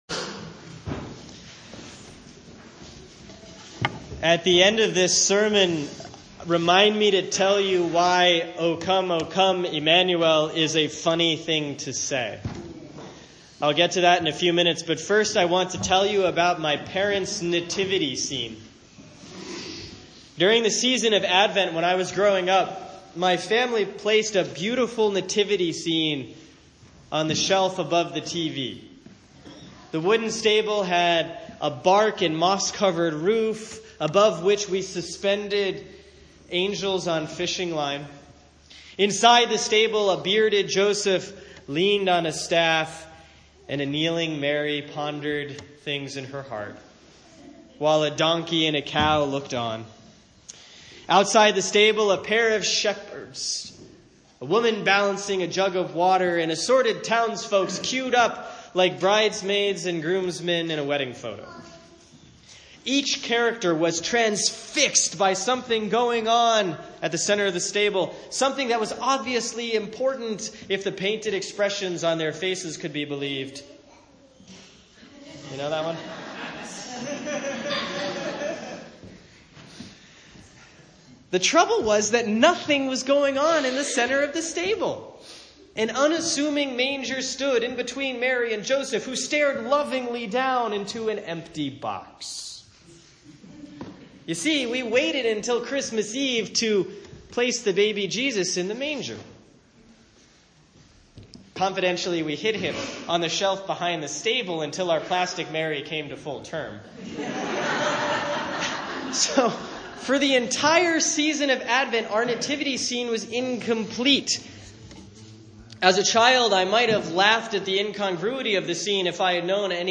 Sermon for Sunday, November 27, 2016 || Advent 1A || Matthew 24:36-44; Romans 13:11-14